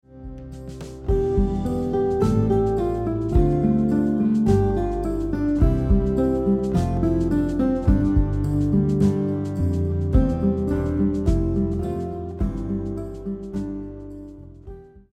• Guitar arrangement